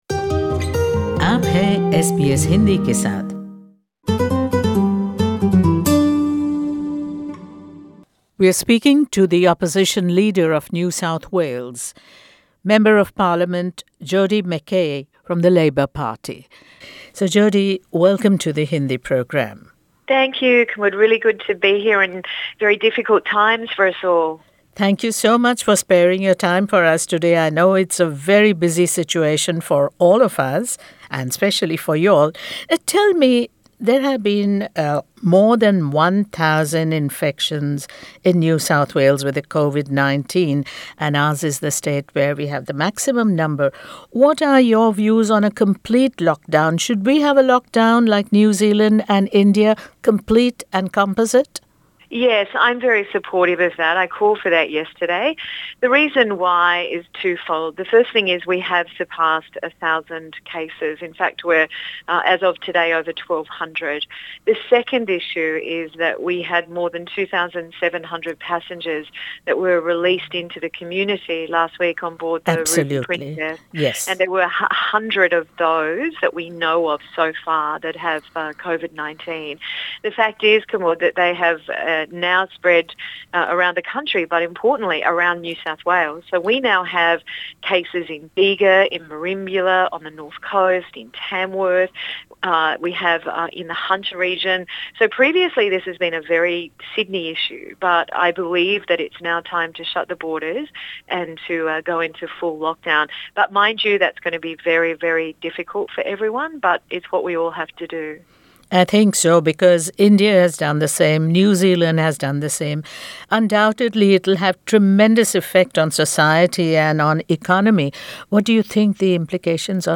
Jodi McKay NSW Opposition Leader Source: Supplied